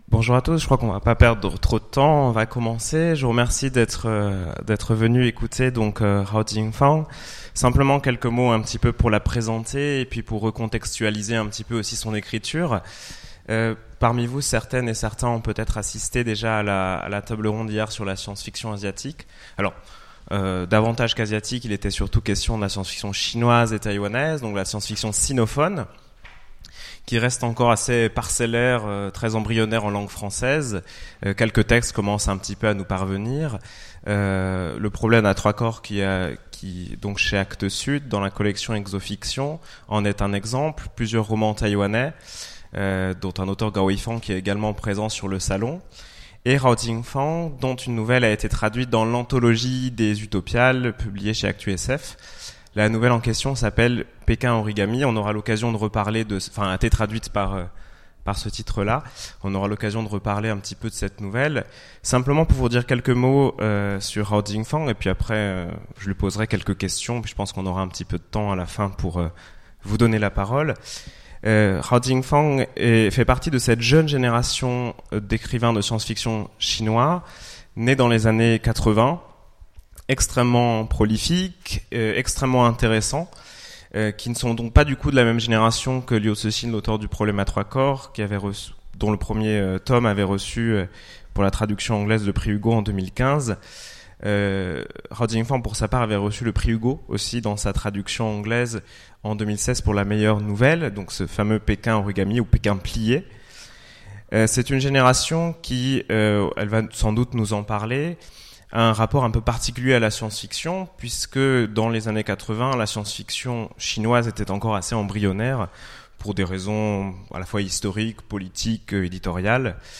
Utopiales 2017 : Rencontre avec Hao Jingfang
- le 15/11/2017 Partager Commenter Utopiales 2017 : Rencontre avec Hao Jingfang Télécharger le MP3 à lire aussi Jingfang Hao Genres / Mots-clés Rencontre avec un auteur Conférence Partager cet article